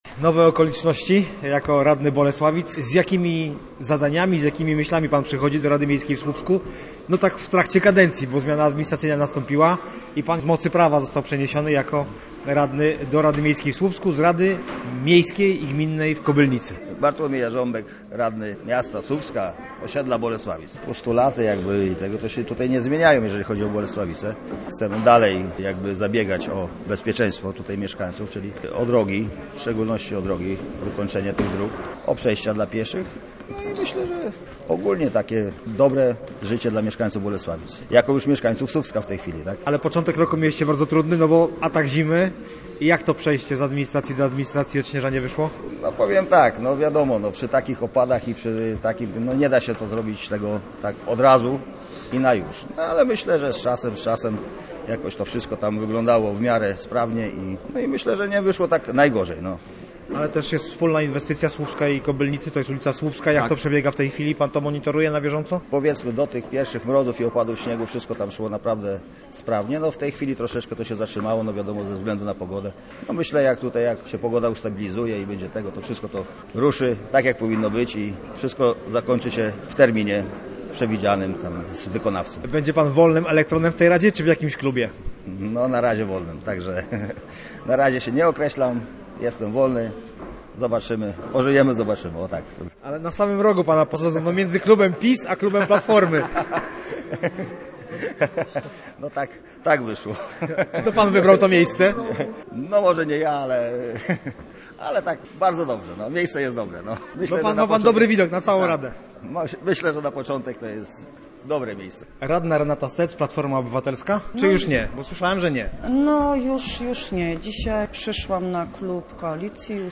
Posłuchaj naszej relacji z sesji Rady Miejskiej w Słupsku: https